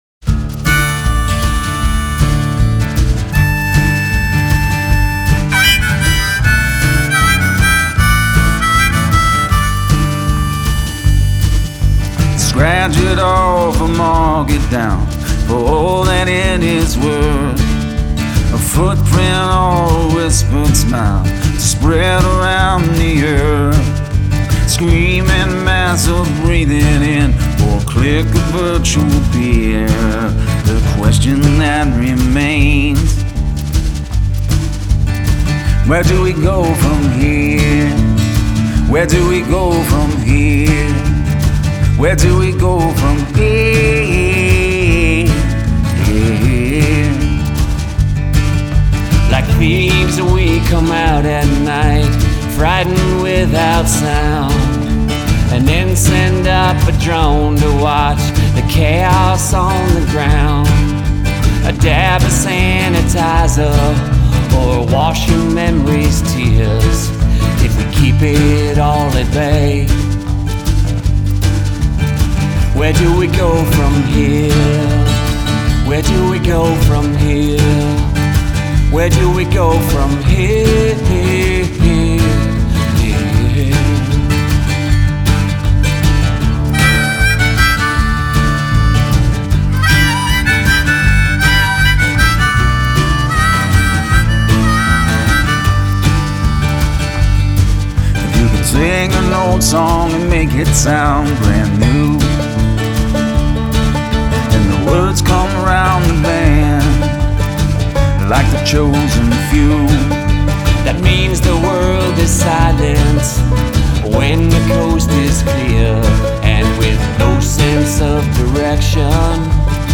vocals, guitar, mandolin, banjo, harmonica, piano